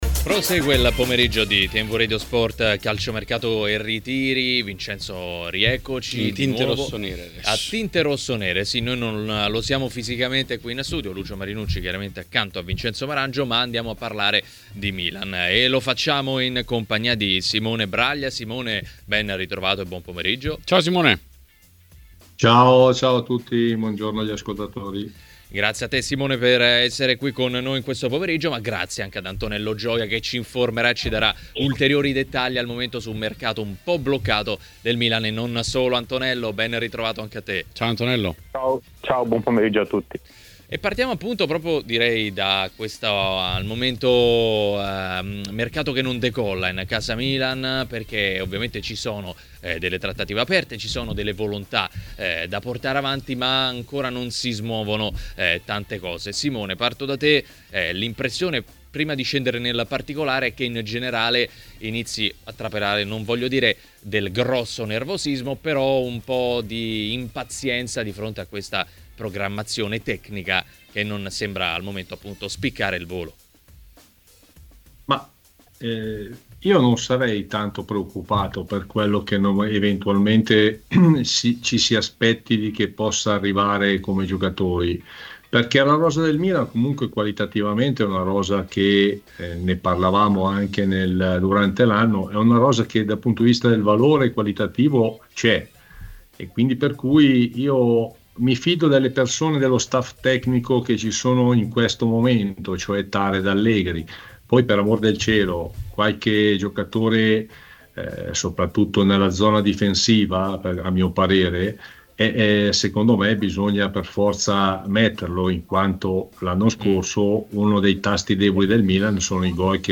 Ospite di Calciomercato e Ritiri, trasmissione di TMW Radio